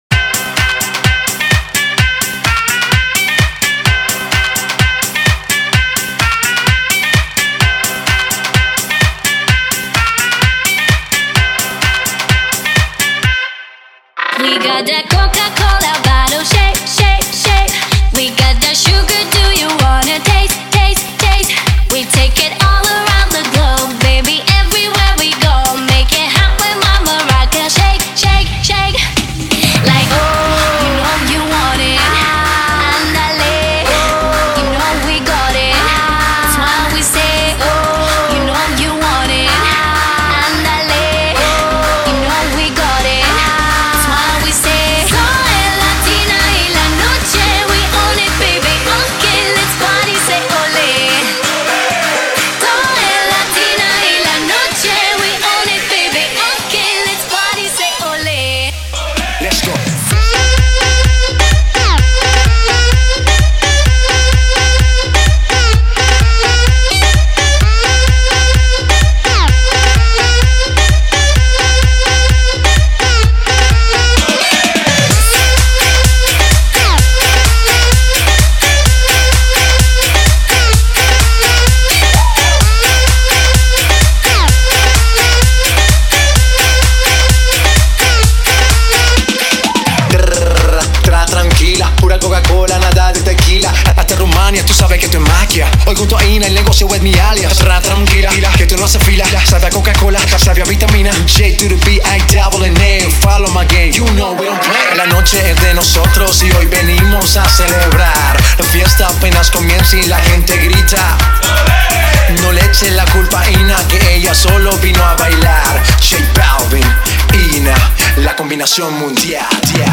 Категория: Ремиксы